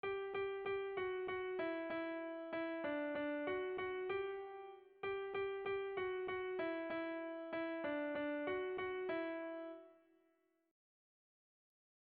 Bertso melodies - View details   To know more about this section
Erromantzea
Gautegiz Arteaga < Busturialdea < Bizkaia < Basque Country
A1A2